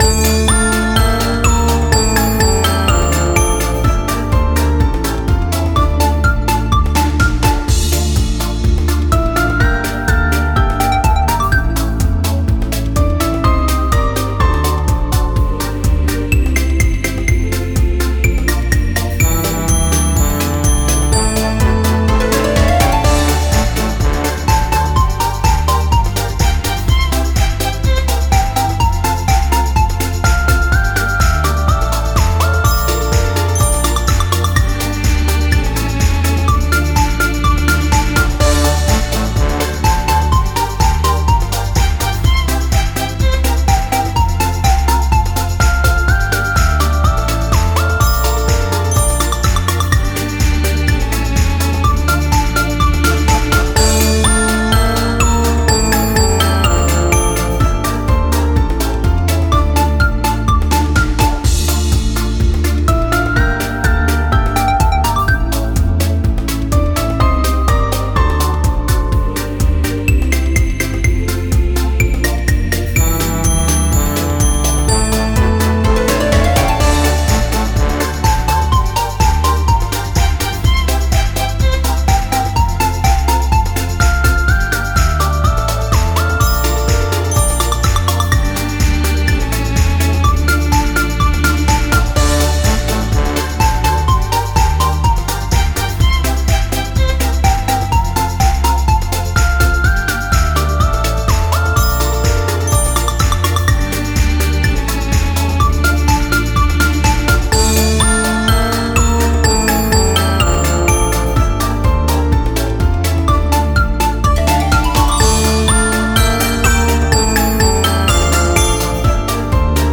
Минус песни